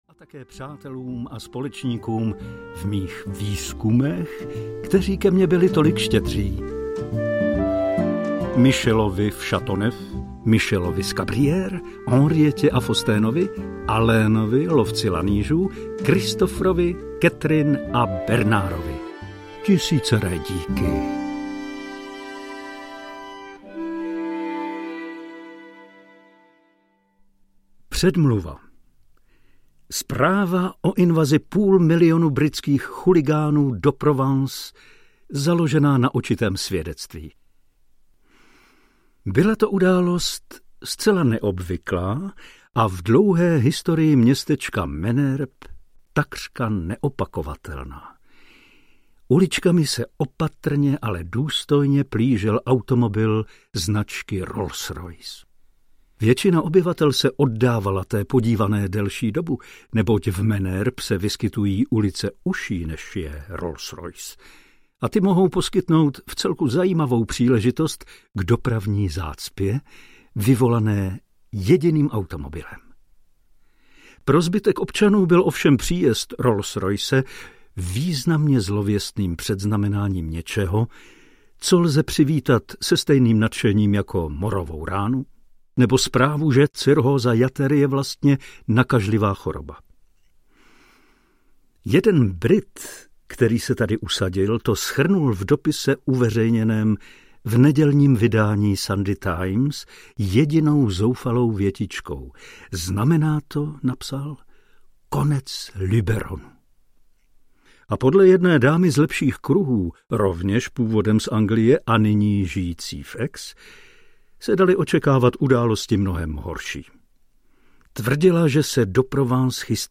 Navždy Provence audiokniha
Ukázka z knihy
• InterpretPavel Soukup